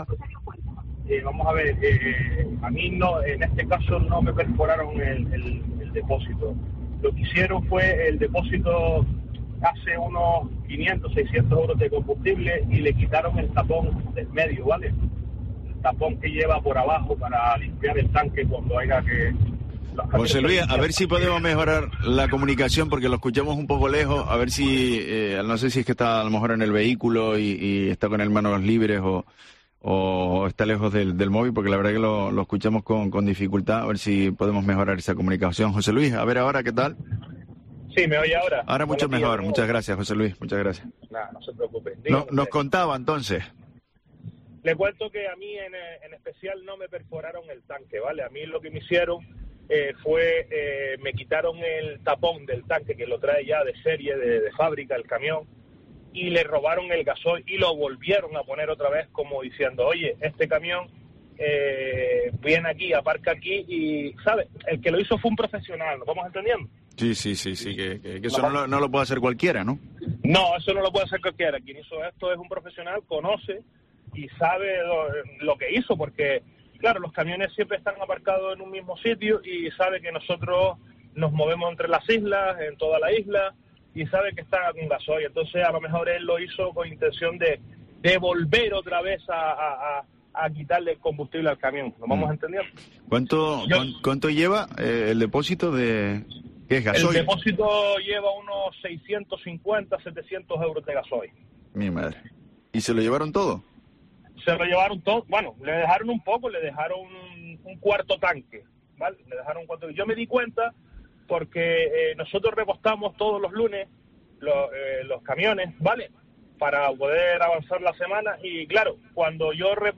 La Mañana de COPE Canarias hablamos con un camionero afectado